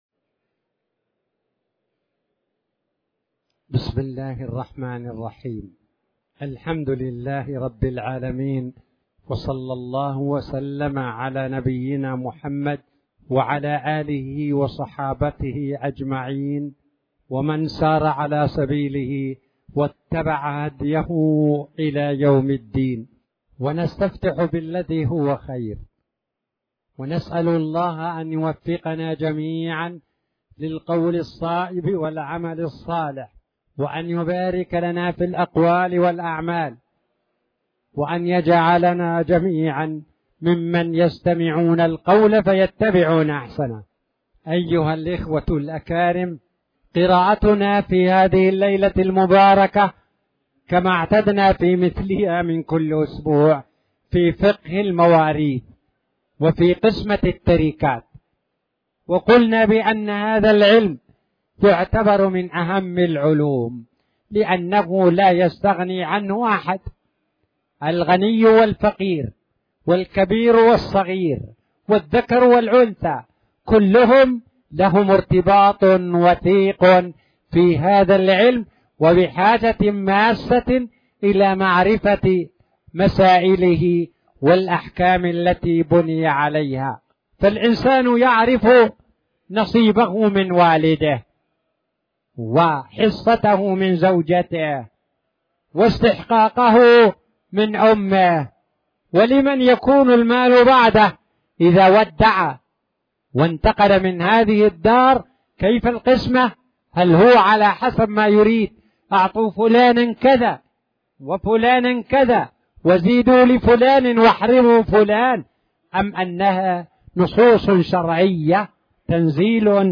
تاريخ النشر ٢٣ جمادى الأولى ١٤٣٨ هـ المكان: المسجد الحرام الشيخ